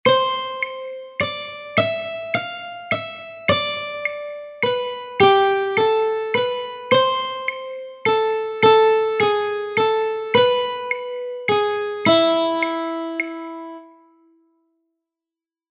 Greensleeves_ternaria.mp3